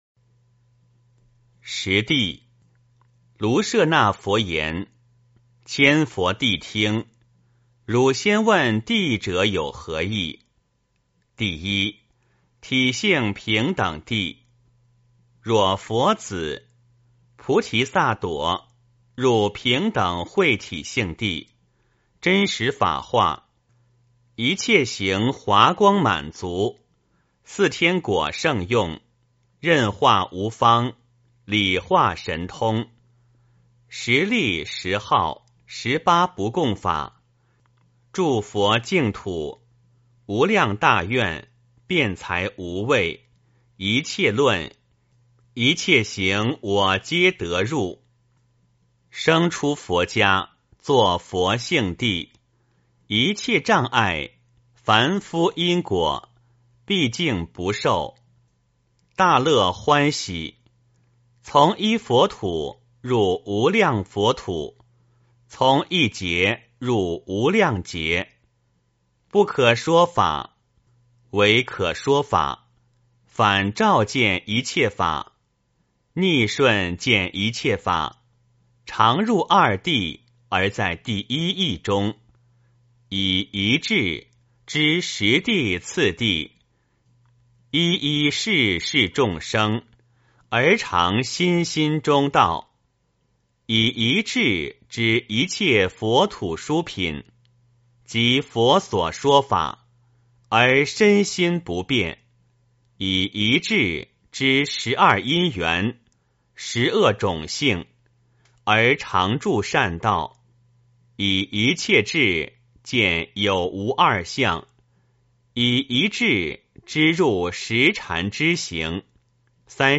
梵网经-十地-体性平等地 - 诵经 - 云佛论坛